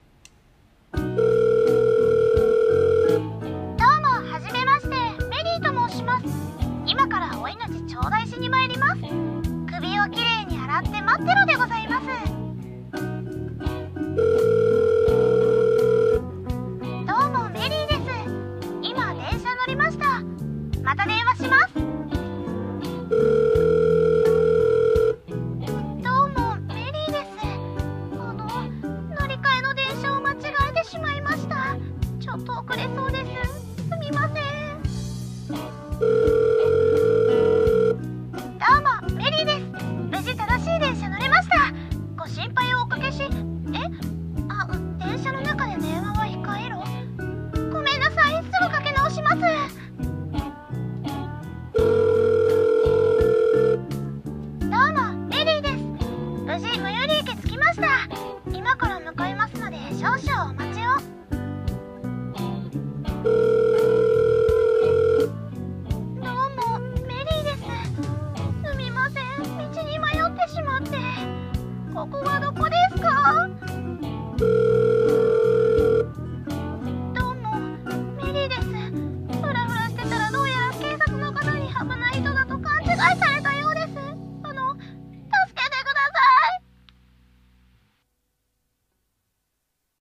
【ギャグ声劇台本】どうも、メリーです。